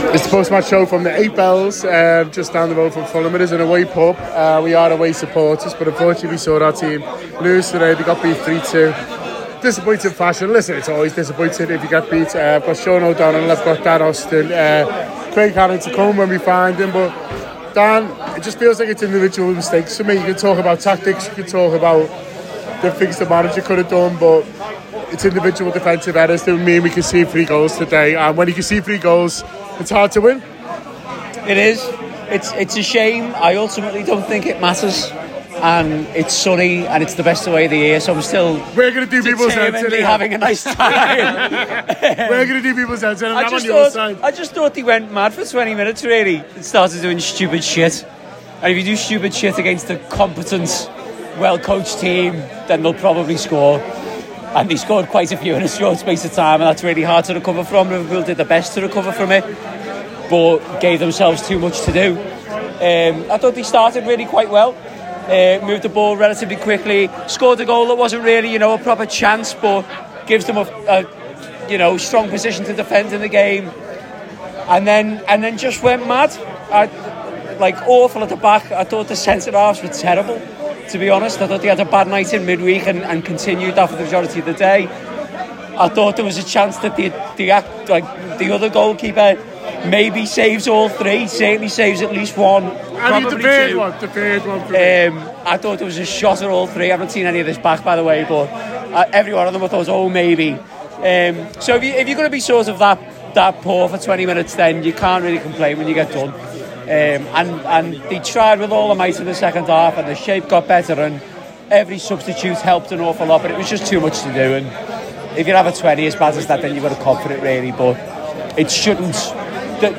The Anfield Wrap’s post-match reaction podcast after Fulham 3 Liverpool 2 at Craven Cottage.